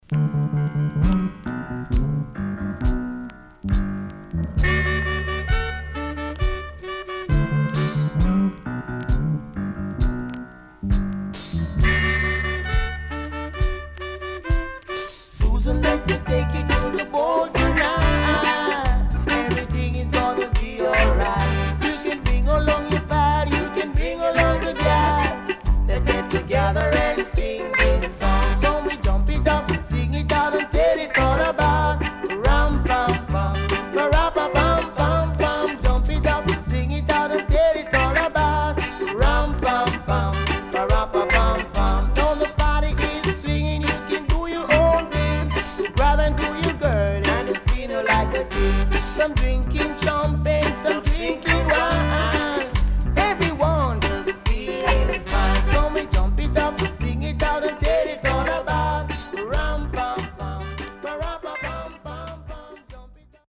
Nice Vocal